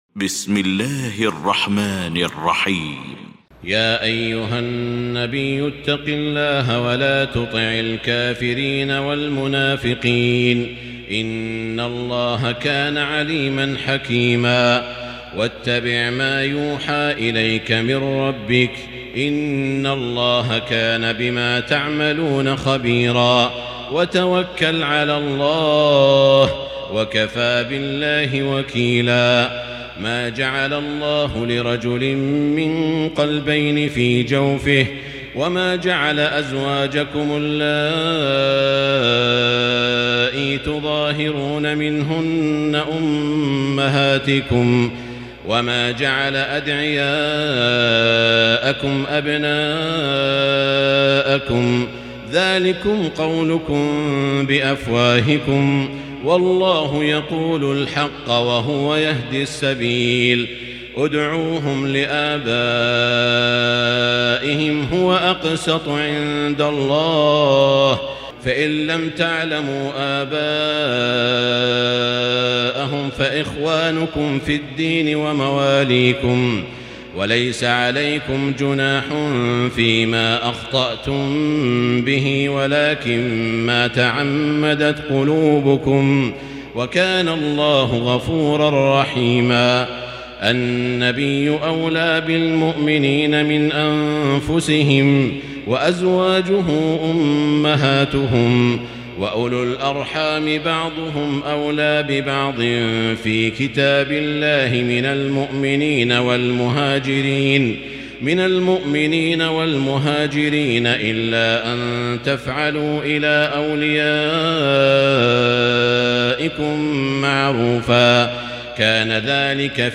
المكان: المسجد الحرام الشيخ: سعود الشريم سعود الشريم فضيلة الشيخ ماهر المعيقلي الأحزاب